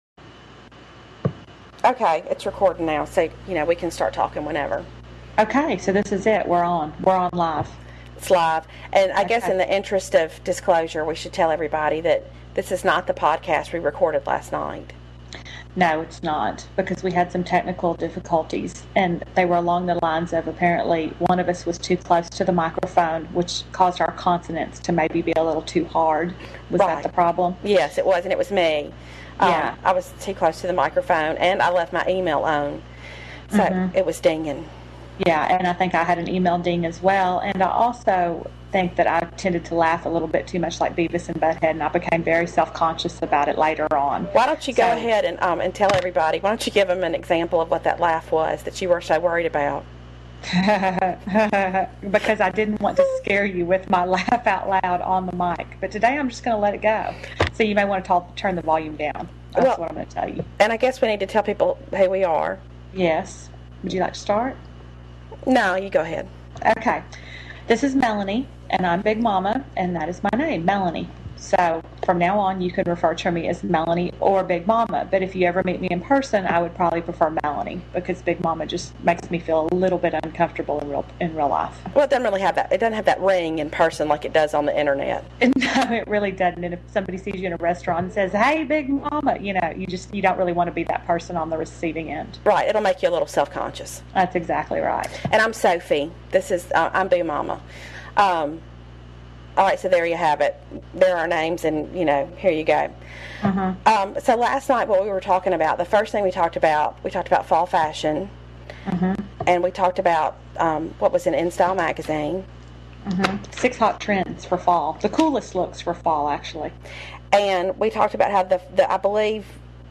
And they really will get a theme song at some point. As well as some better editing skills.